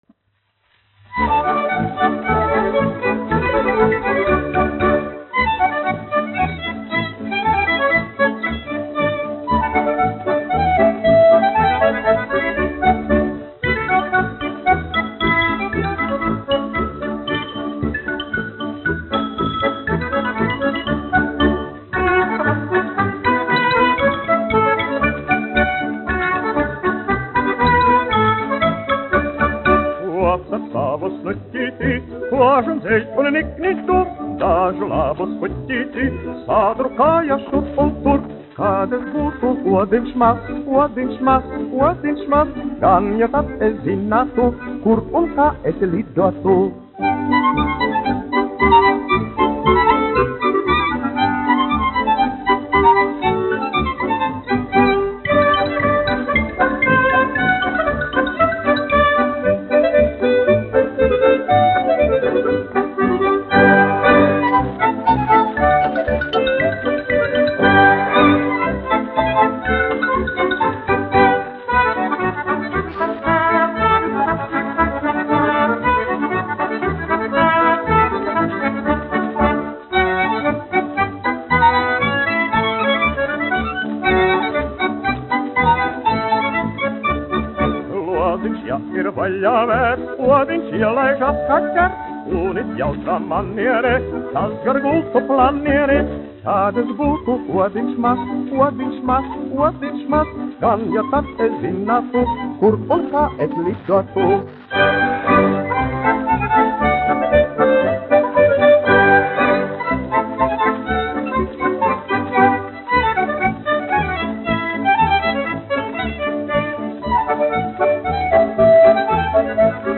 1 skpl. : analogs, 78 apgr/min, mono ; 25 cm
Polkas
Skaņuplate